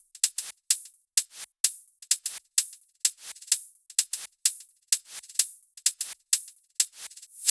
VFH3 128BPM Wobble House Kit
VFH3 128BPM Wobble House Kit 7.wav